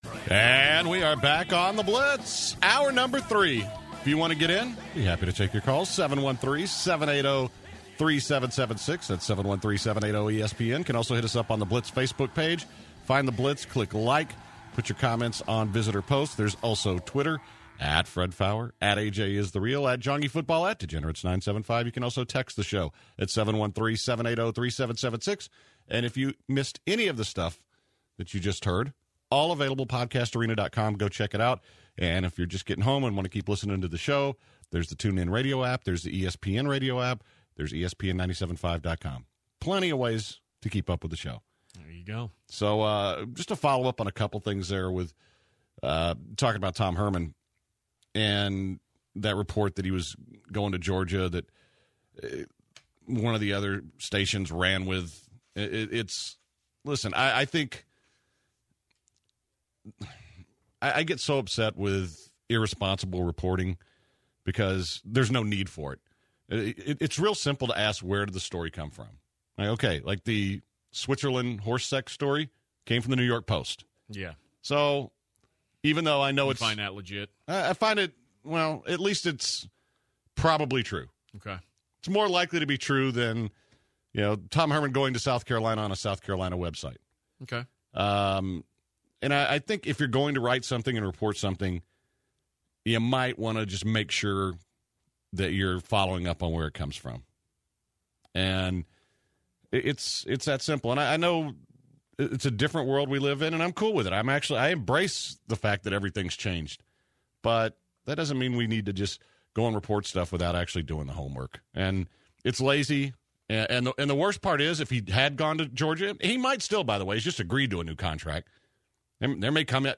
The guys discuss bowl game predictions, future standings in the NFL Playoffs, Tom Herman Rumors. and the DA report